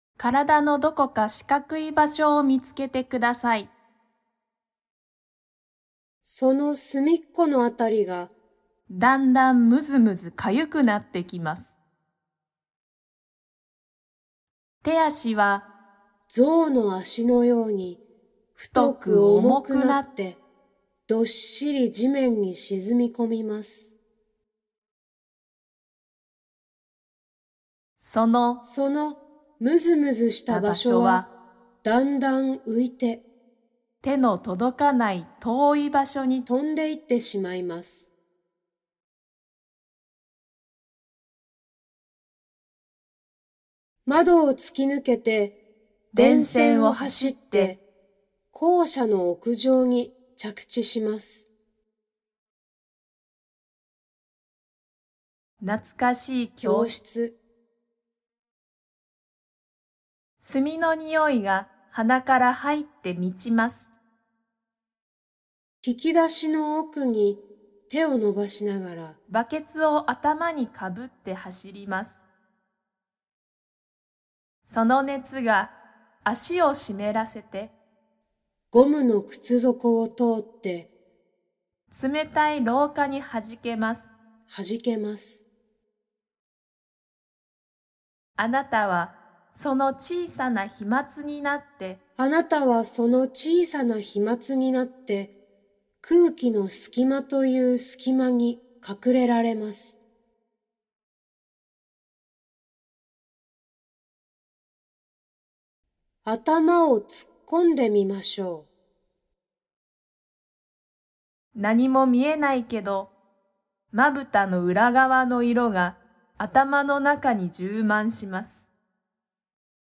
音声によるインストラクション